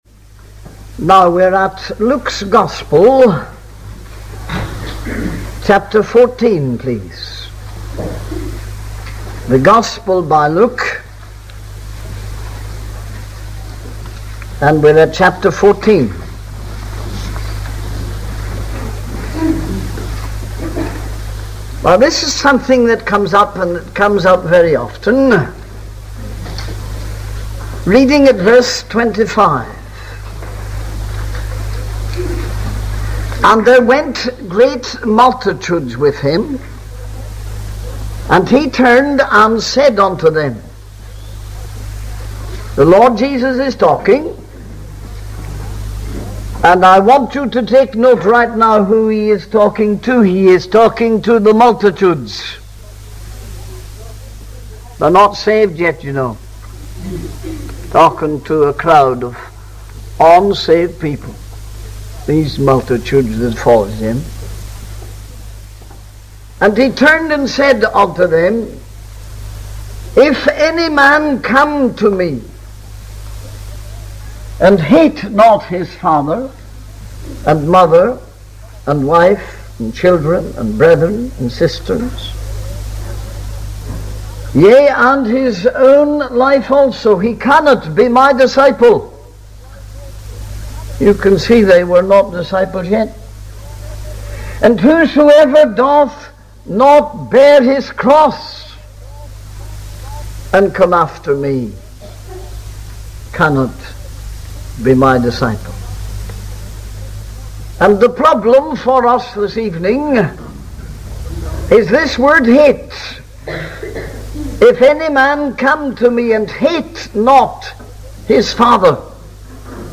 In this sermon, the preacher focuses on the Ten Commandments given by God to Moses.